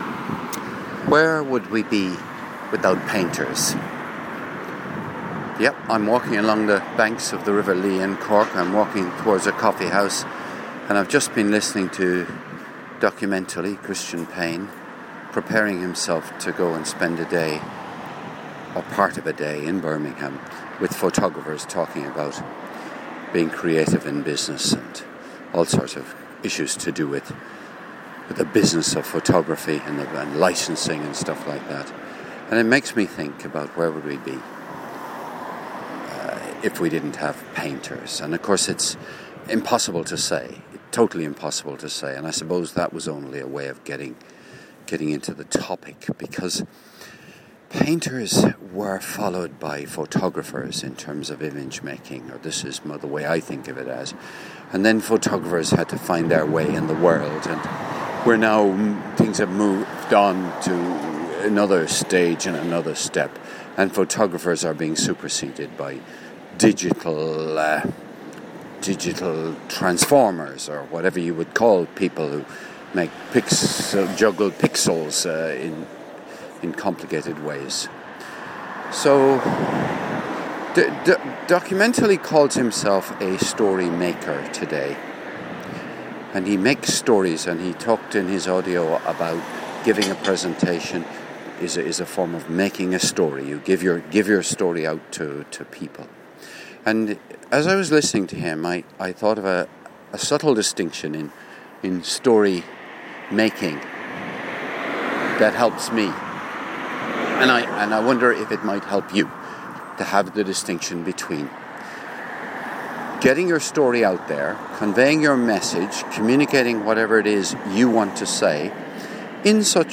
Recorded on Saturday 14 June 2014 - while street walking in the little city of Cork & finished off hours later in my house with children playing in the background